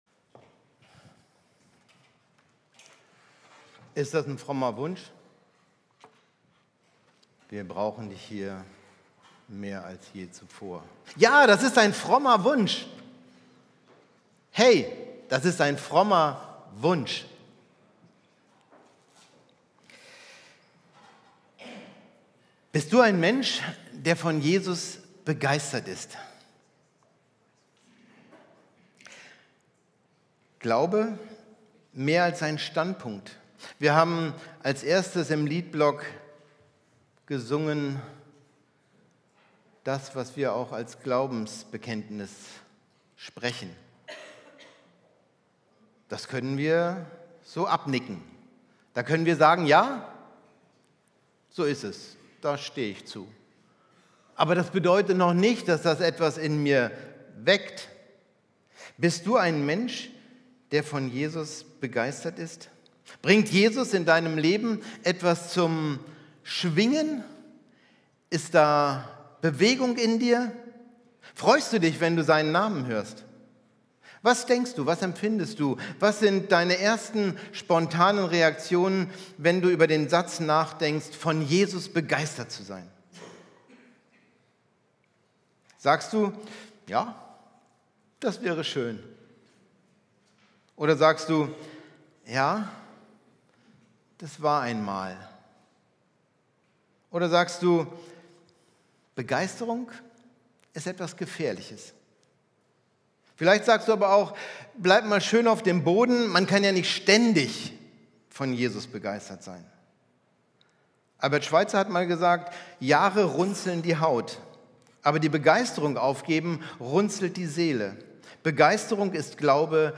Predigt mp3